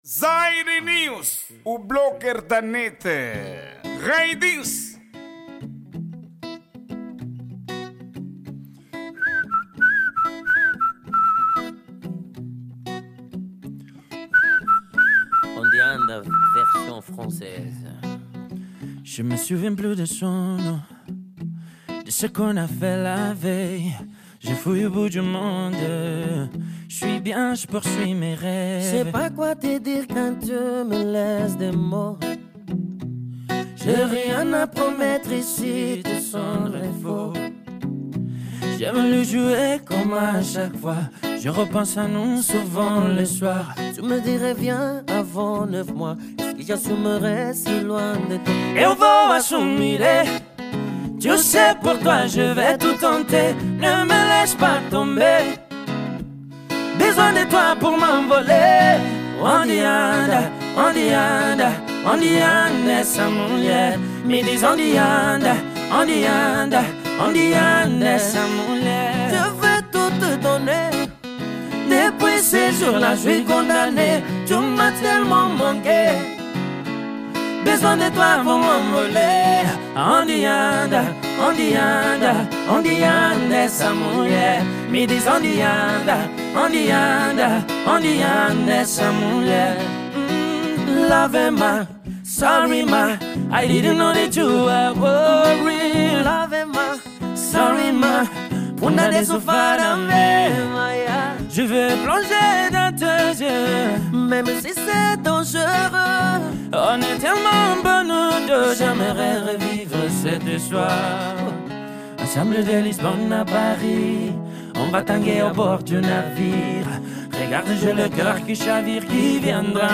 Gênero:zouk